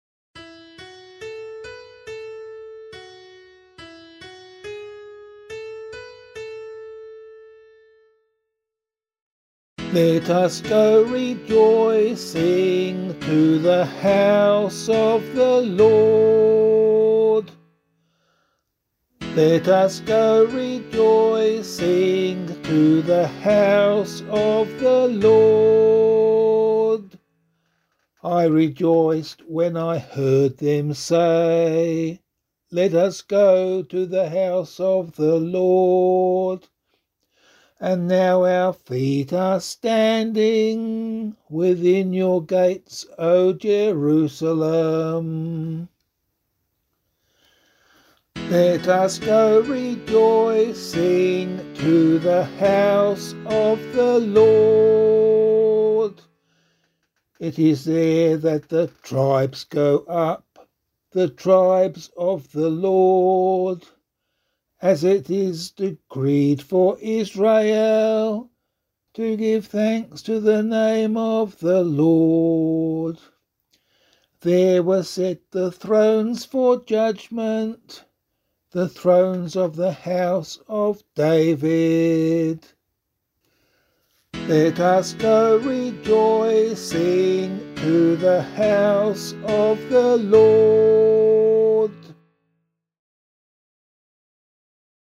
068 Christ the King Psalm C [APC - LiturgyShare + Meinrad 8] - vocal.mp3